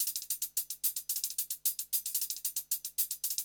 HIHAT LOP9.wav